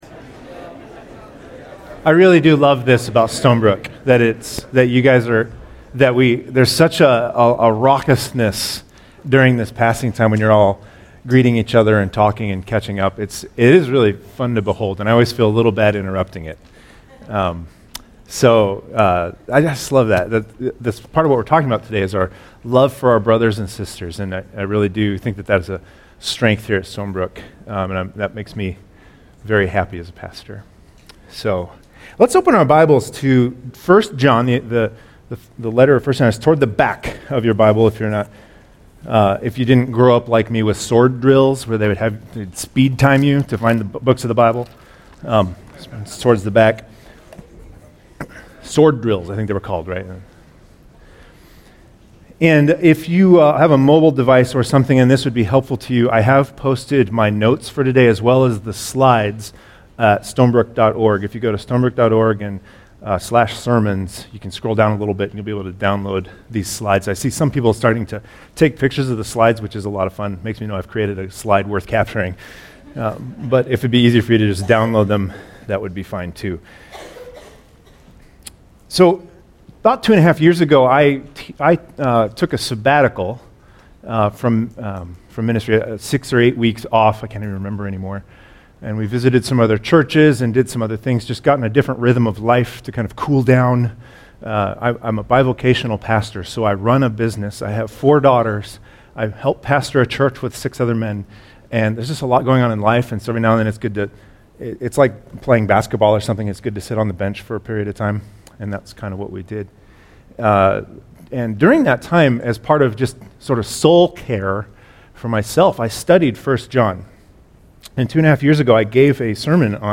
Download sermon slides